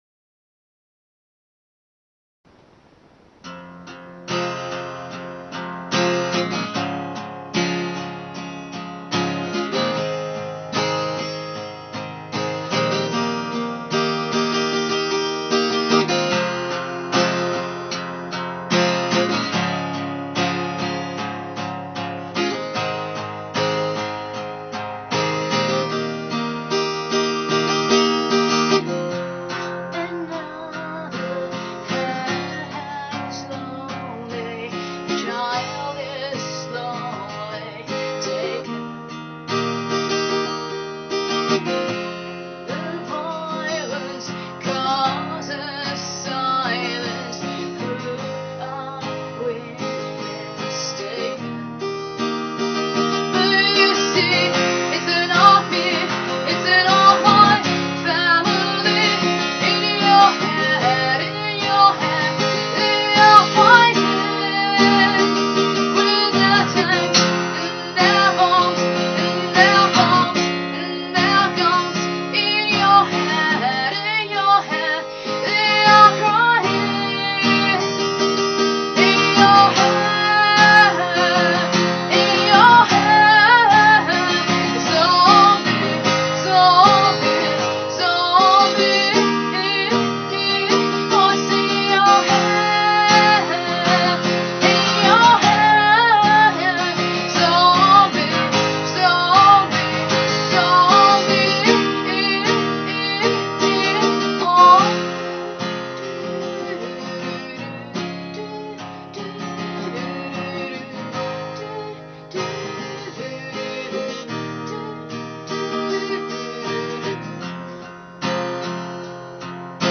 English songs